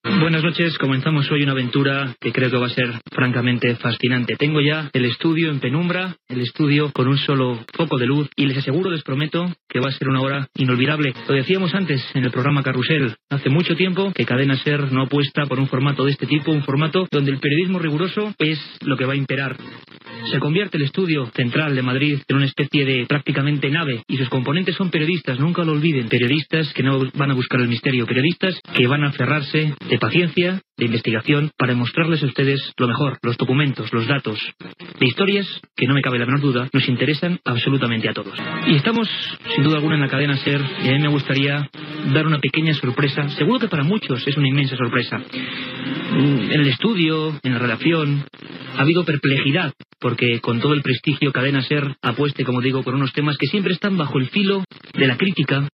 Paraules de presentació del primer programa amb l'objectiu de l'equip que el fa
Divulgació